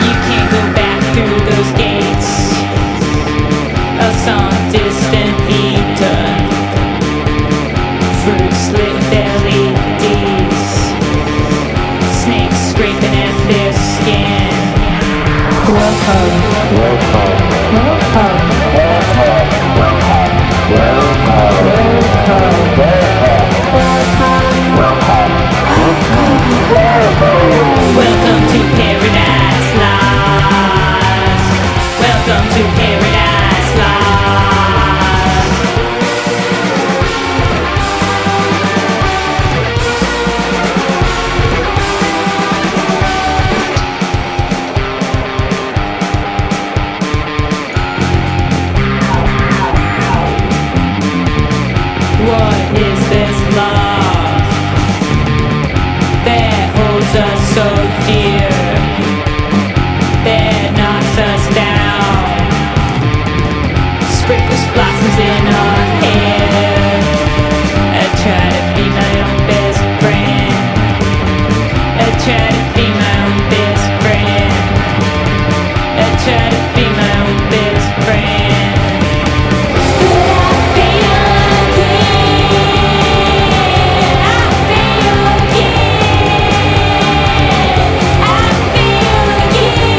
JAPANESE GUITAR POP
SHOEGAZER